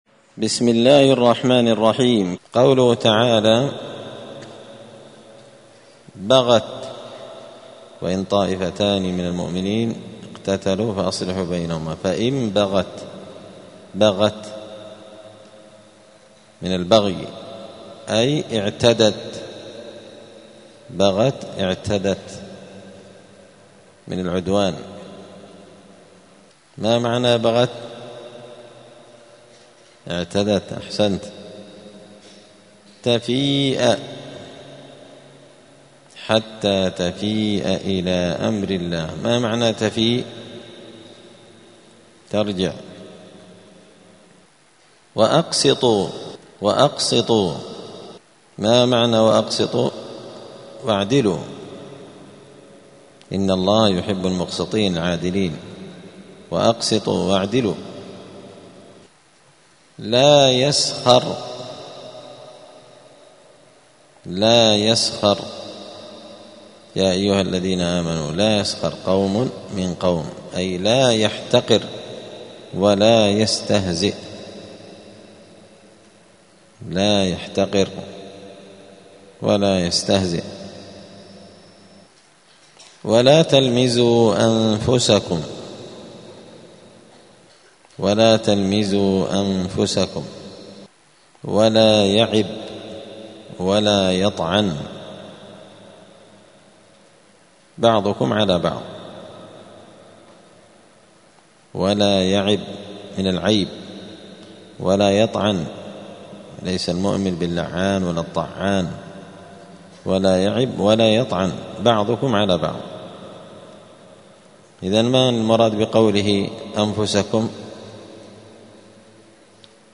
الأربعاء 11 جمادى الأولى 1446 هــــ | الدروس، دروس القران وعلومة، زبدة الأقوال في غريب كلام المتعال | شارك بتعليقك | 25 المشاهدات